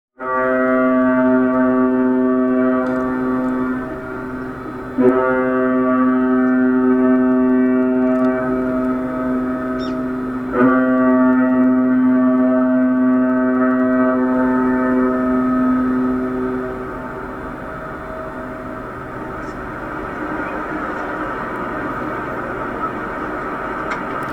ドラの音が響き、重厚な汽笛を鳴らしながら名古屋港を出航する豪華客船「飛鳥U」は華麗な出航風景でした。
飛鳥U汽笛の音です
kiteki.mp3